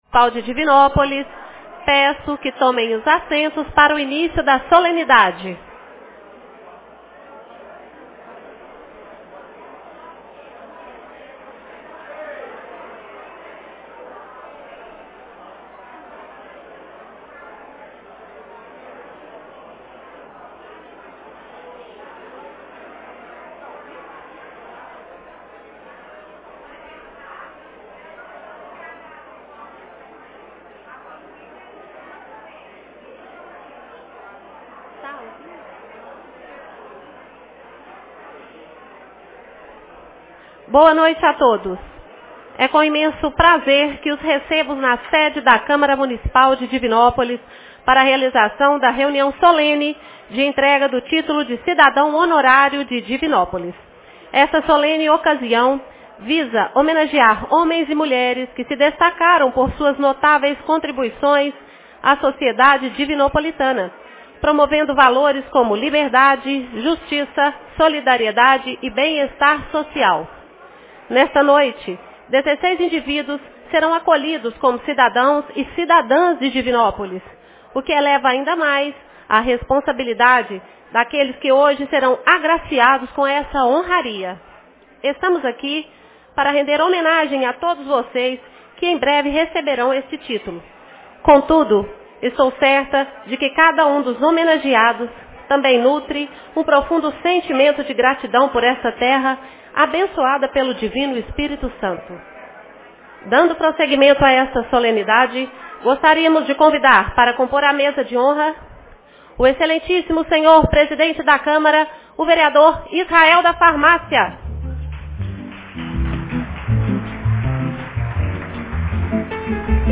Reuniões Especiais